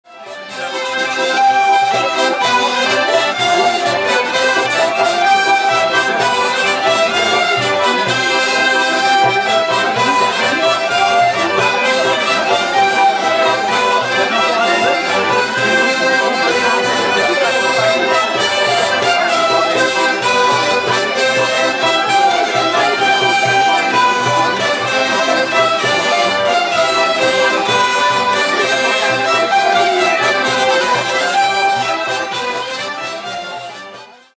LA FÊTE DE LA VIELLE
Au coin de la rue, un orchestre s'improvise : accordéon diatonique, vielle, accordéon chromatique... et que la musique commence !